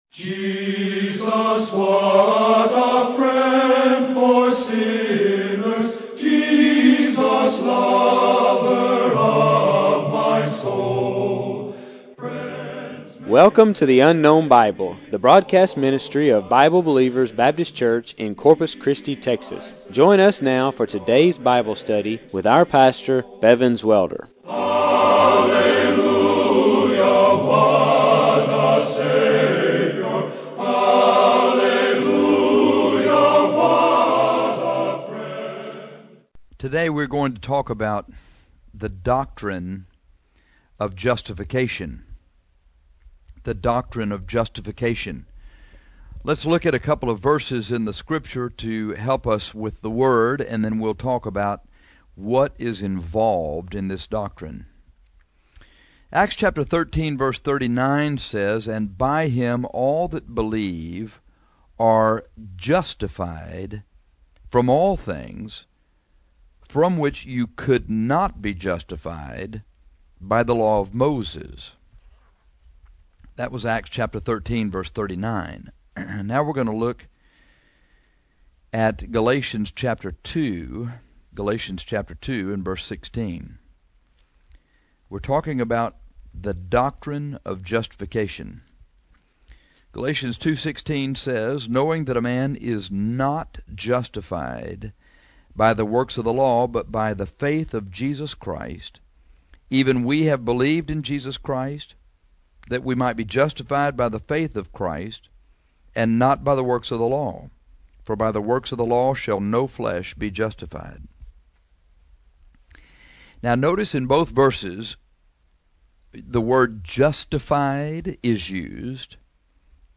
This lesson is on the doctrine of justification.